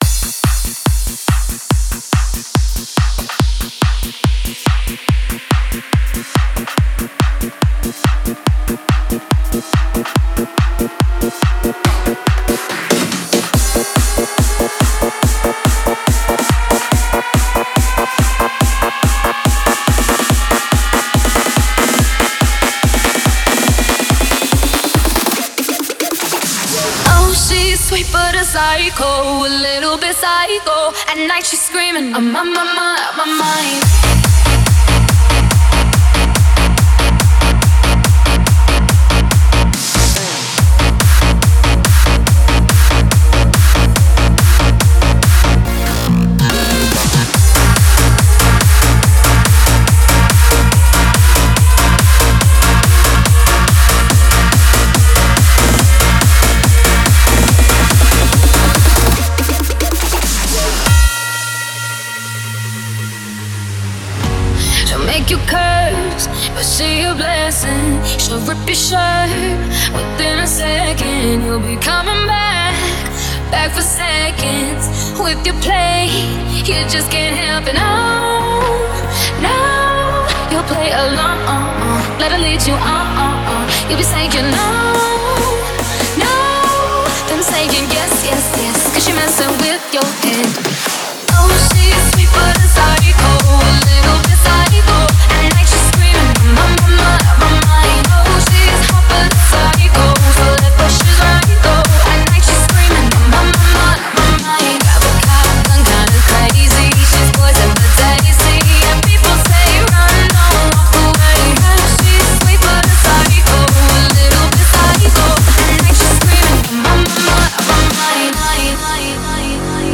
Hands Up song